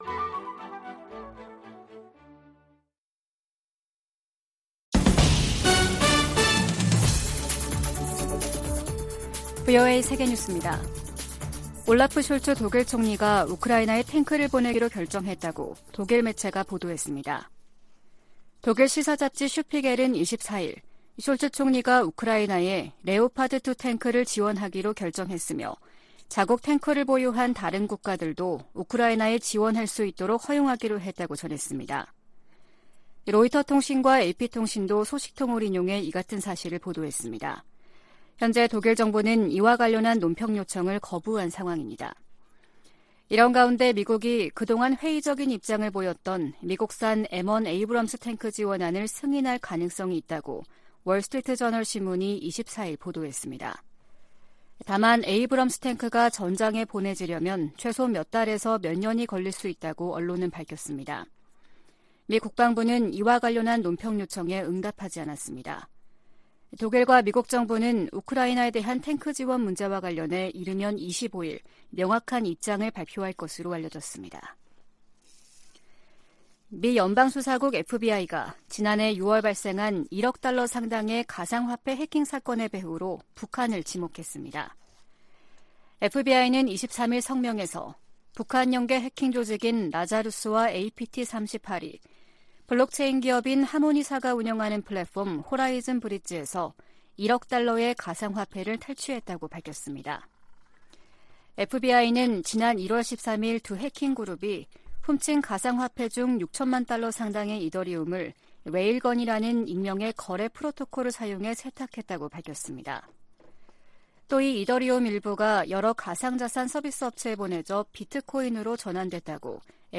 VOA 한국어 아침 뉴스 프로그램 '워싱턴 뉴스 광장' 2023년 1월 25일 방송입니다. 국무부는 북한과 러시아 용병 회사 간 무기거래와 관련해 한국 정부와 논의했다고 밝혔습니다. 북한에서 열병식 준비 정황이 계속 포착되는 가운데 평양 김일성 광장에도 대규모 인파가 집결했습니다.